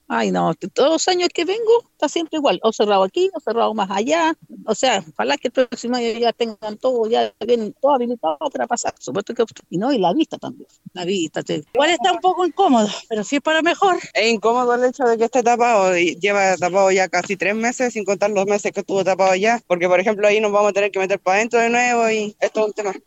La Radio se dirigió hasta la costanera para conocer la opinión de los transeúntes que aseguran que el cerco perimetral, que sigue instalado en el lugar, obstruye el camino para continuar con su trayecto.
cuna-transeuntes-costanera.mp3